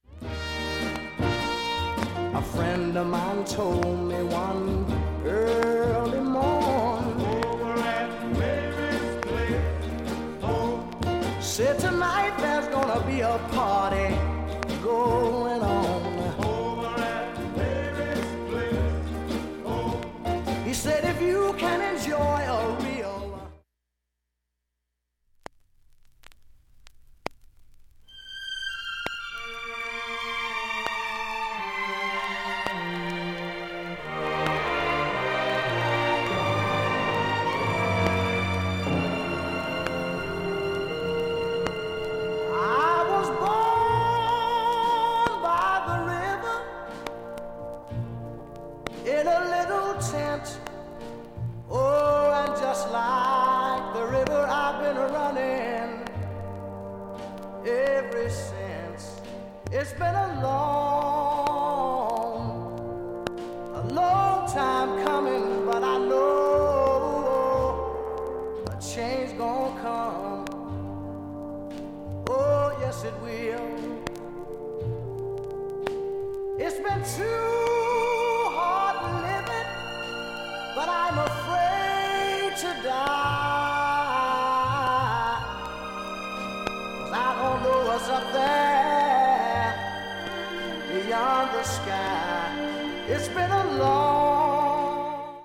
音質良好全曲試聴済み。
72秒の間に周回プツが出ます。
◆ＵＳＡ盤オリジナル Mono